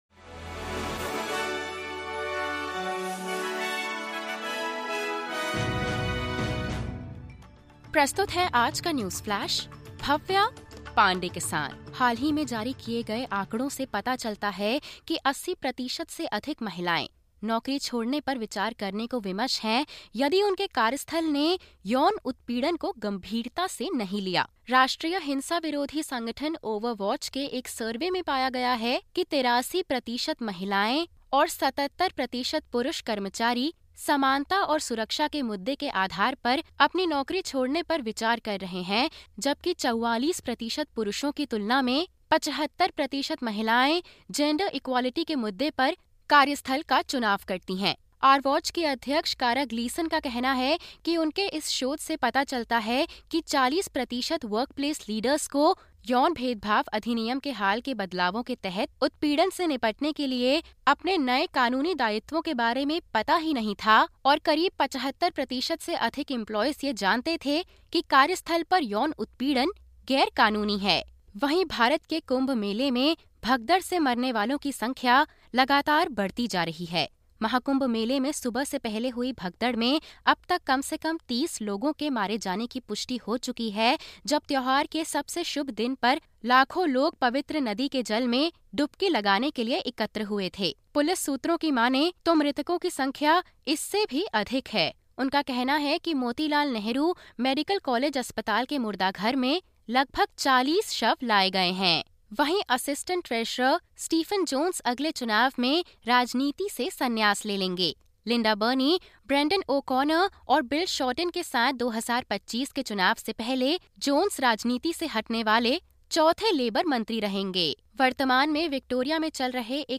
सुनें ऑस्ट्रेलिया और भारत से 30/01/2025 की प्रमुख खबरें।